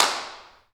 Claps
OAK_clap_mpc_07.wav